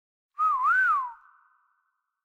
Pick Up Man Whistle
adult blog calm cartoon casual catchy cinematic comedy sound effect free sound royalty free Movies & TV